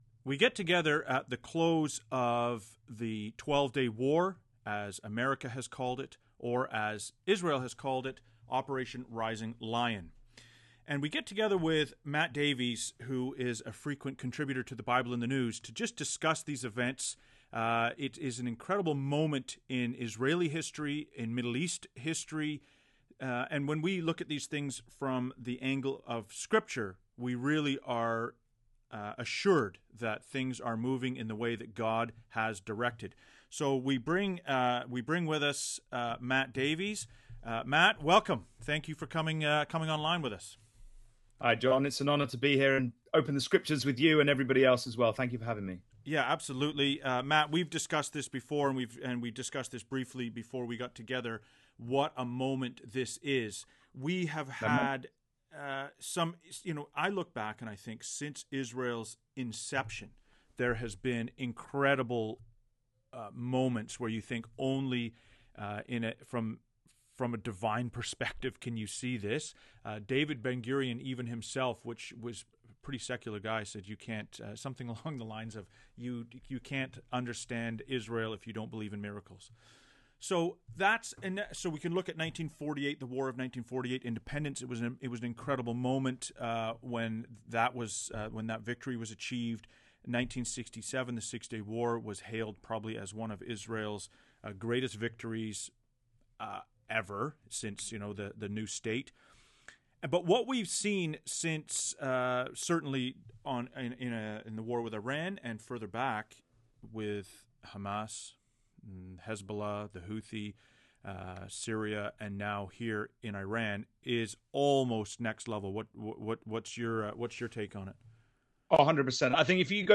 The 12 Day War and Bible Prophecy An Unscripted! conversation on current events in light of Bible Prophecy.